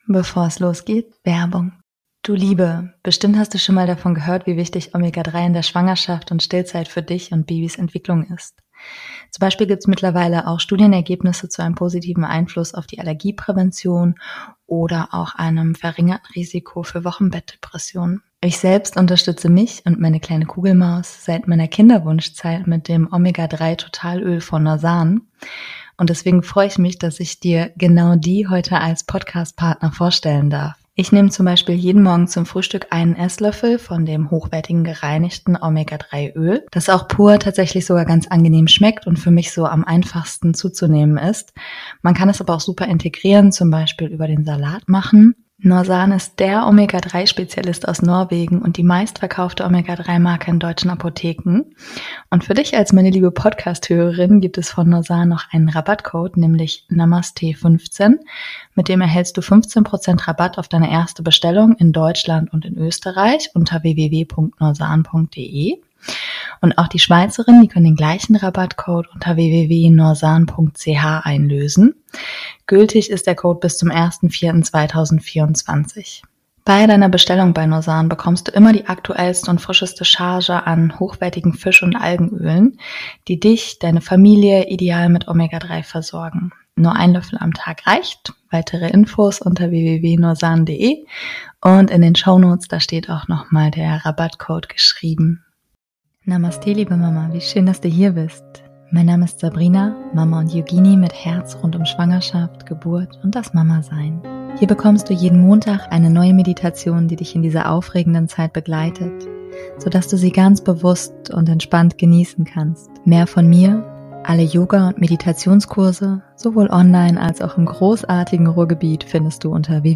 #172 - Achtsamkeitsmeditation zum Stillen